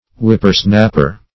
Whippersnapper \Whip"per*snap`per\, n.